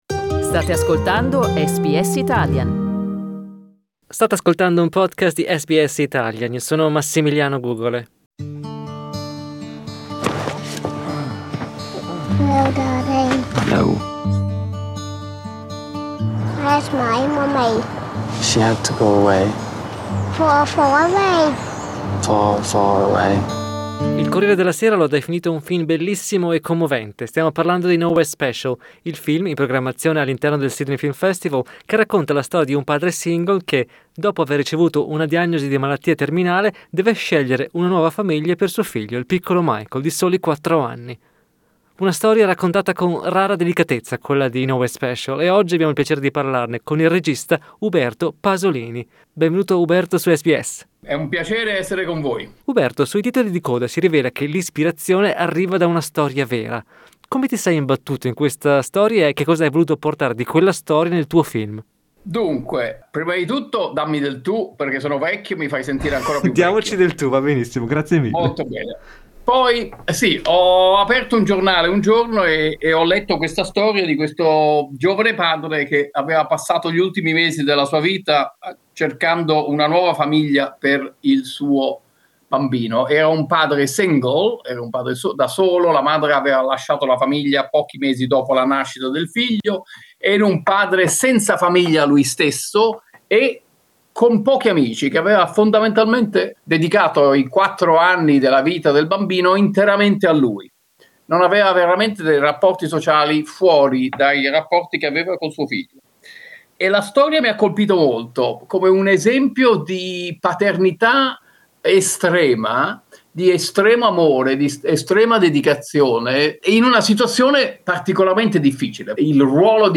Così Uberto Pasolini racconta a SBS Italian l'avventura di Nowhere Special, che ha scritto, diretto e prodotto.